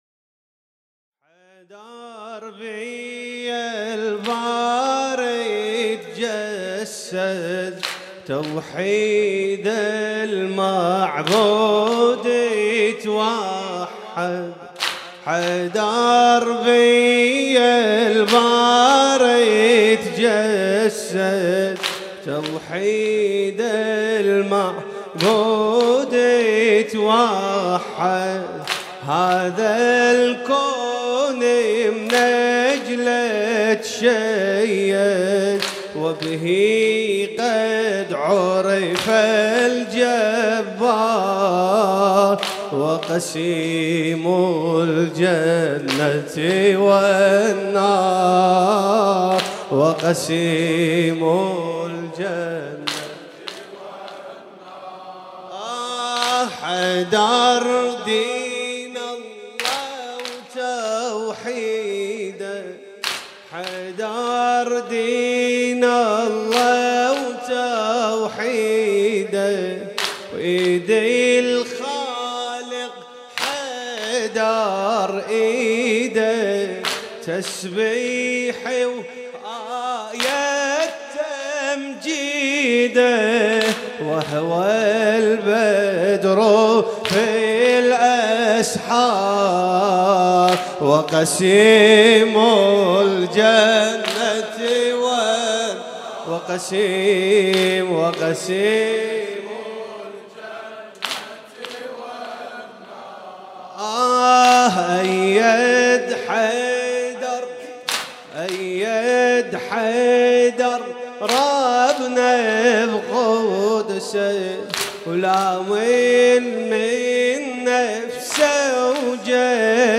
شب بیستم رمضان 96 - هیئت شبان القاسم - و قسیم الجنة و النار